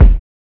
LO FI 9 BD.wav